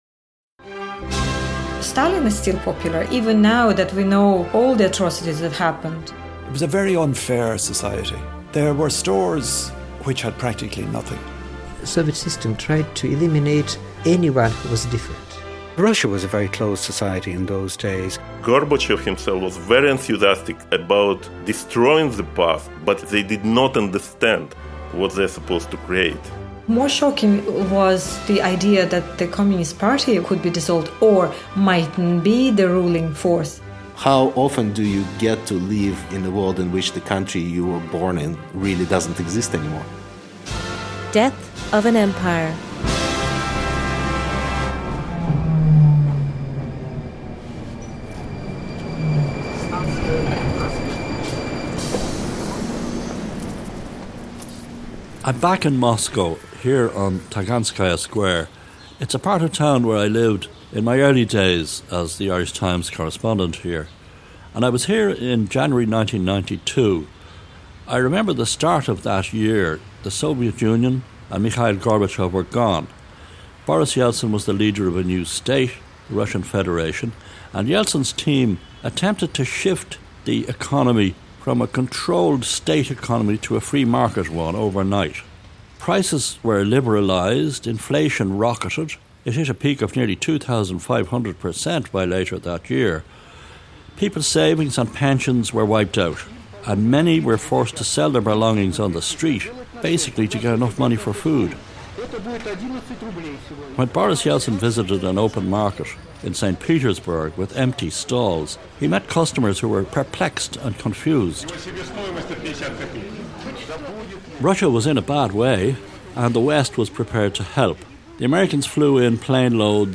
We hear from the then Minister of Foreign Affairs, Gerry Collins on Yeltsin’s Russia.